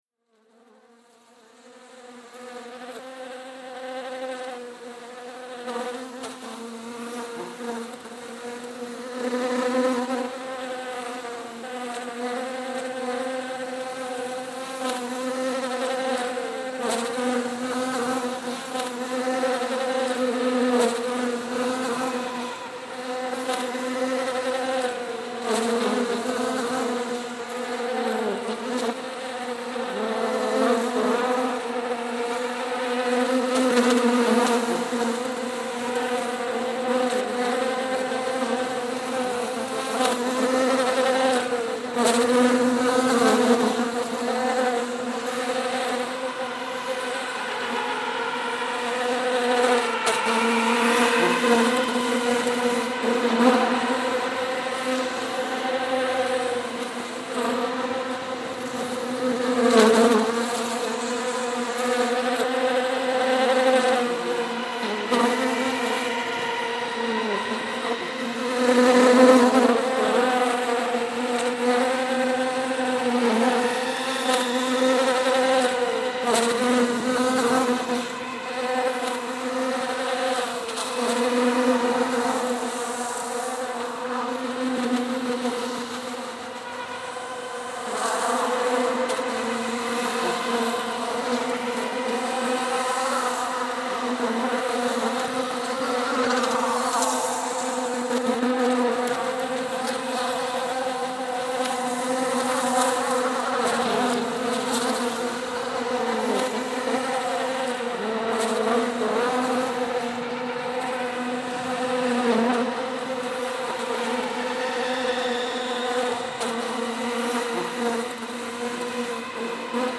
The Modular Active Bees Soundscape.